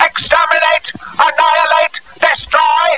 Dalek from Dr.